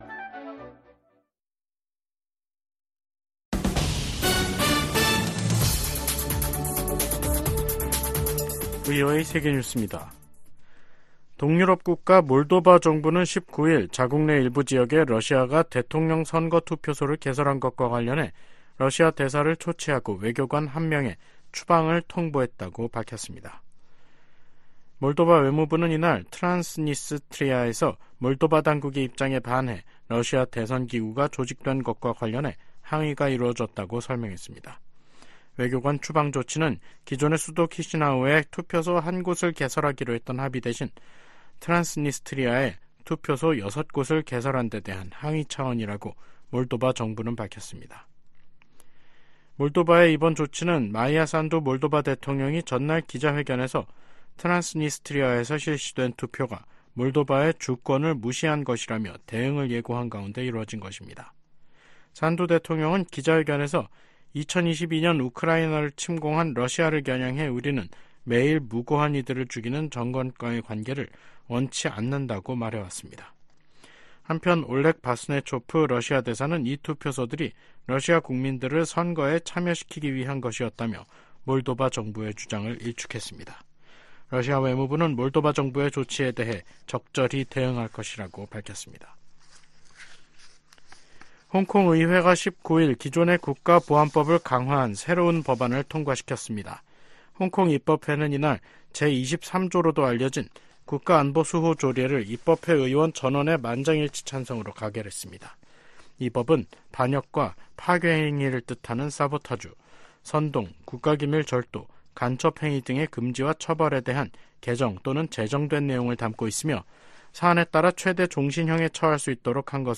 VOA 한국어 간판 뉴스 프로그램 '뉴스 투데이', 2024년 3월 19일 3부 방송입니다. 린다 토머스-그린필드 유엔 주재 미국 대사가 북한의 17일 단거리 탄도미사일 발사를 비판했습니다. 김정은 북한 국무위원장은 18일 한국 수도권 등을 겨냥한 초대형 방사포 사격훈련을 지도하며 위협 수위를 높였습니다. 미국 대선과 한국 총선을 앞둔 올해 북한의 임박한 공격 징후는 보이지 않는다고 정 박 미 국무부 대북 고위관리가 말했습니다.